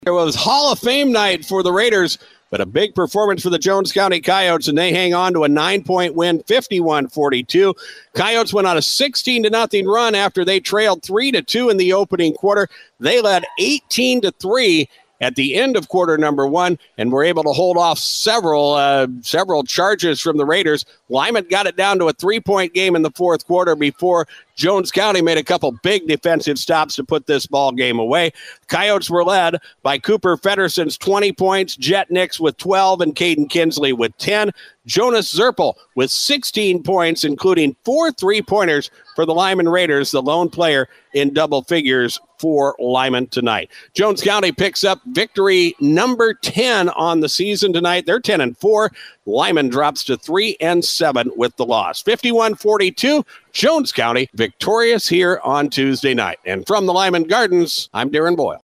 Jones County defeated Stanley County 51 to 42 in South Dakota High School boys basketball action Tuesday (Jan. 23, 2024) night. DRG sportscaster
recap